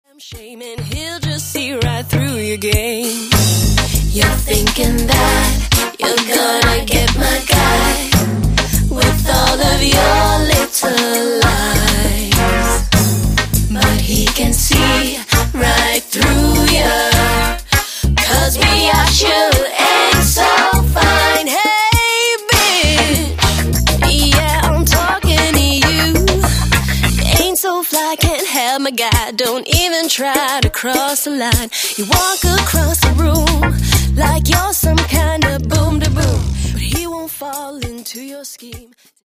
pop/r&b
contemporary sounds of guitar-based pop rock with
smooth and sexy r&b rhythms and reggae-tinged